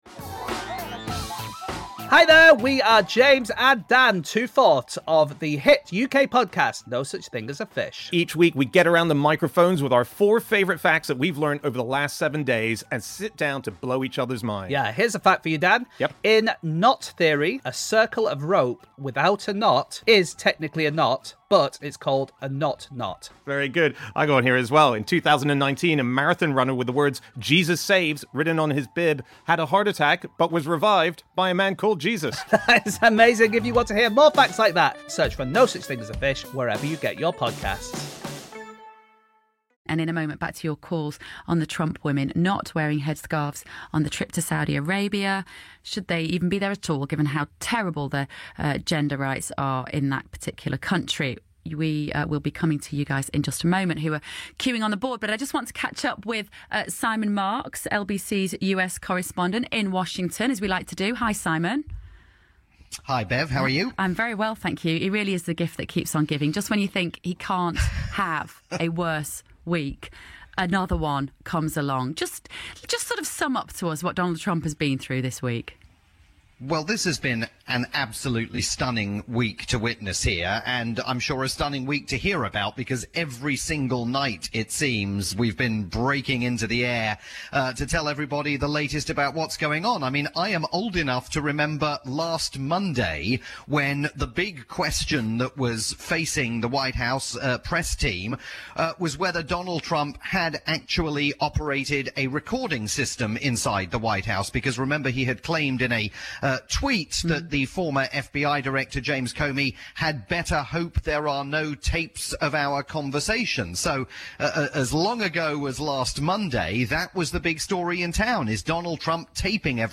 Beverley Turner and I review an amazing week in US history. Via her Saturday evening show on the UK's top talk station LBC.